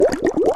Sfx Boat Through Water Sound Effect
Download a high-quality sfx boat through water sound effect.
sfx-boat-through-water-2.mp3